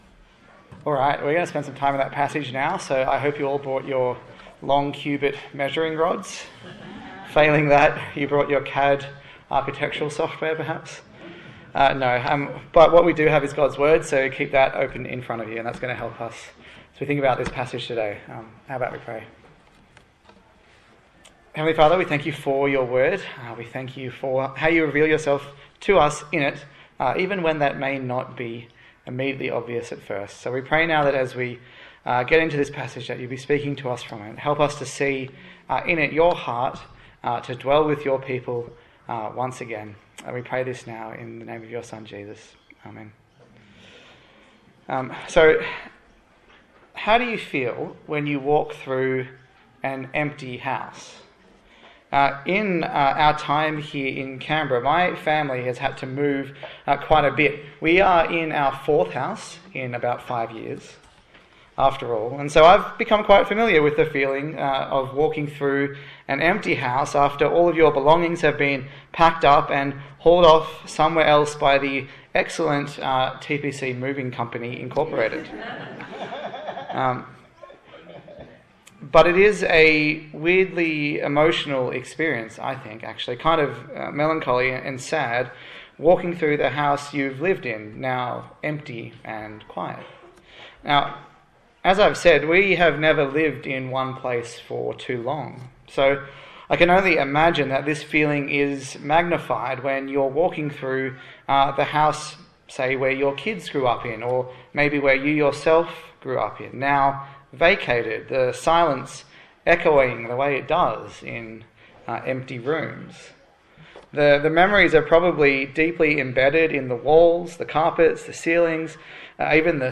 Service Type: Morning Service A sermon in the series on the book of Ezekiel